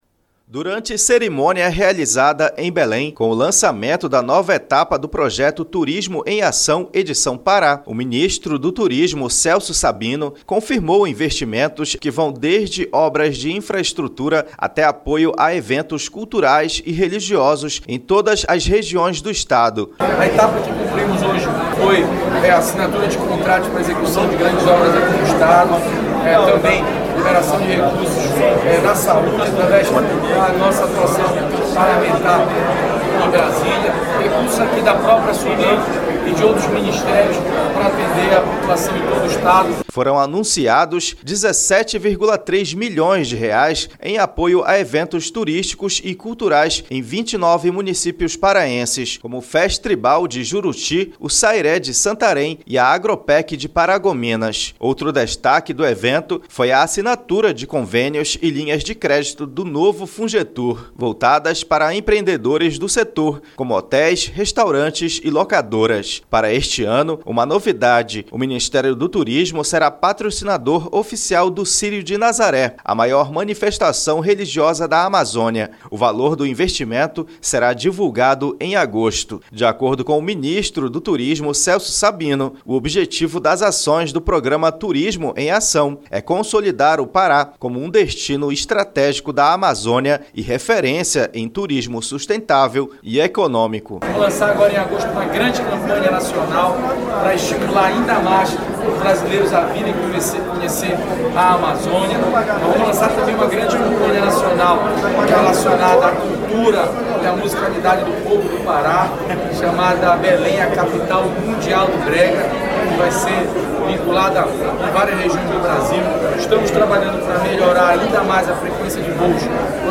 Durante cerimônia realizada em Belém, o ministro do turismo, Celso Sabino, destacou ações que vão desde obras de infraestrutura até apoio a eventos culturais e religiosos em todas as regiões do Pará. Confira na reportagem.